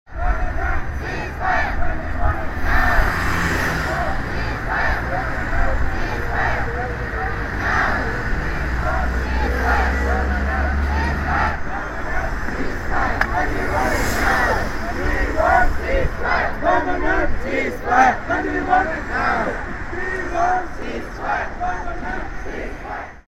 Sri Lankan protest 2009
Tags: Rallies and demonstrations Rallies and demonstrations clips UK London Rallies and demonstrations sounds